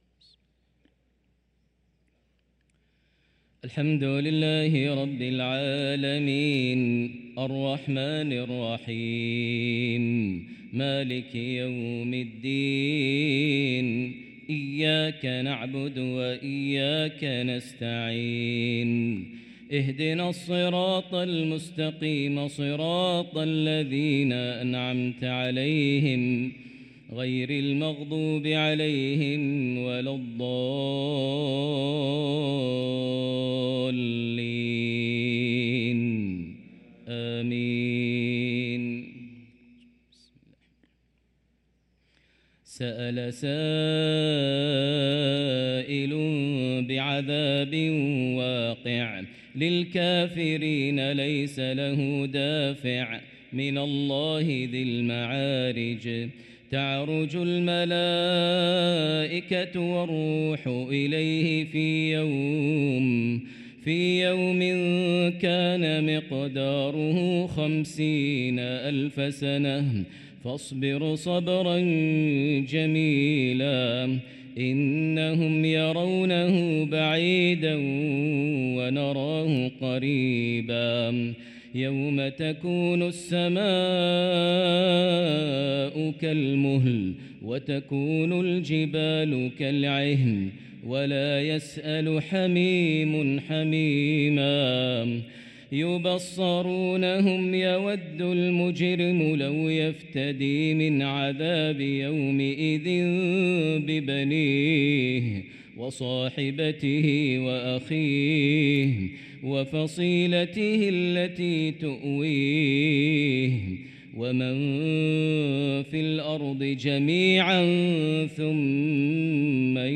صلاة العشاء للقارئ ماهر المعيقلي 28 ربيع الآخر 1445 هـ
تِلَاوَات الْحَرَمَيْن .